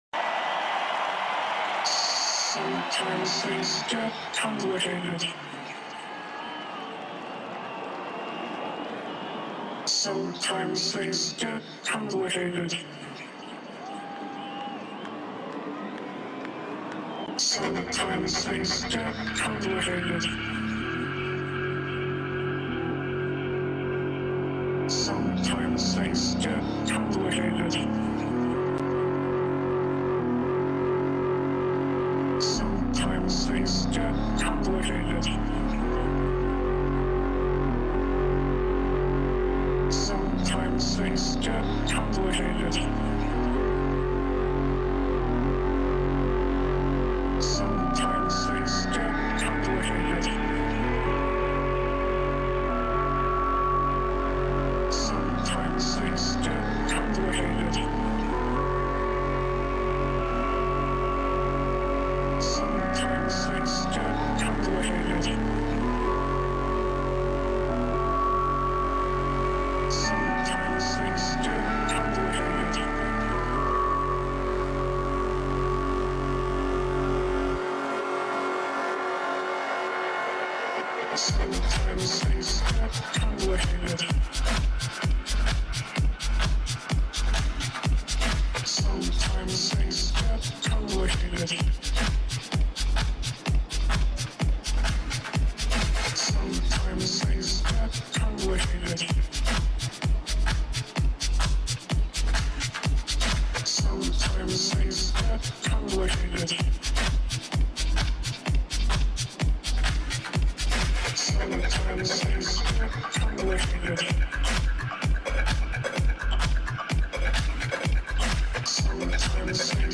Live at Roskilde Festival